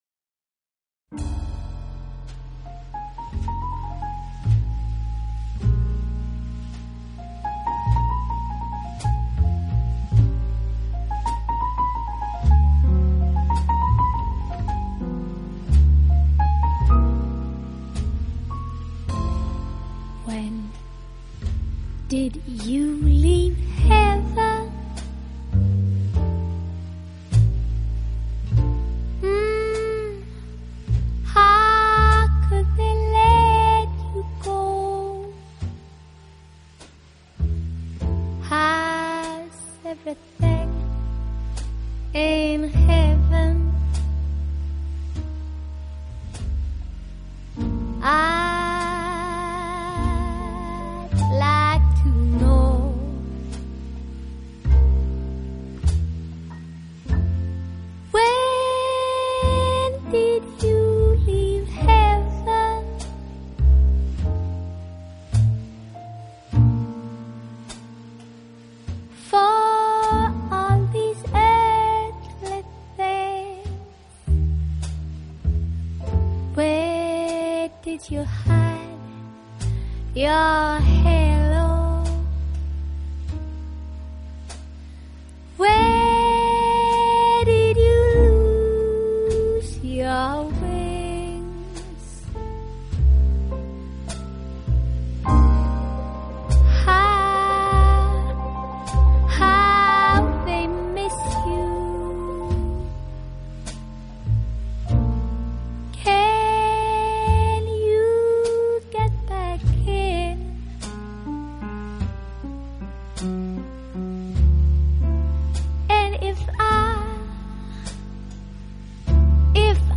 【爵士女声】
歌声却有
如雏燕般稚气，令人闻之倍感怜惜。
三重奏是瑞典着名的爵士乐团，
晰且动态慑人。